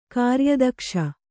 ♪ kāryadakṣa